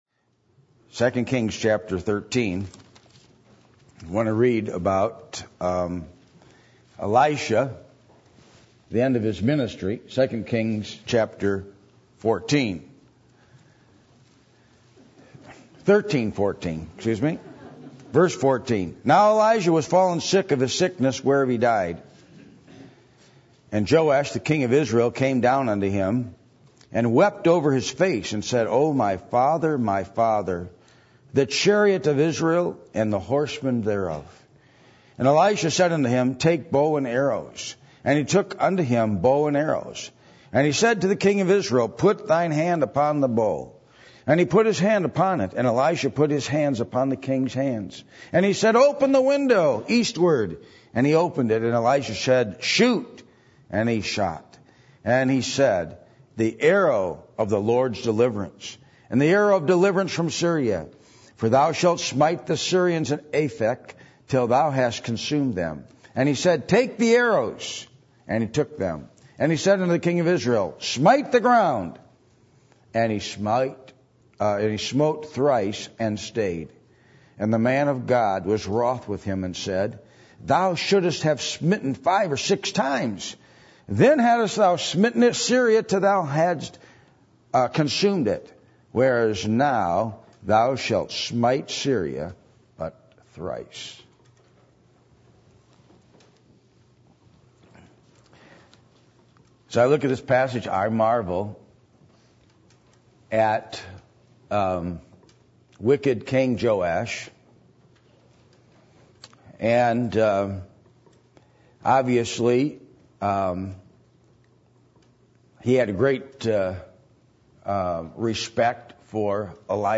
2 Kings 13:14-19 Service Type: Sunday Evening %todo_render% « Do You Have The Joy Of The Lord?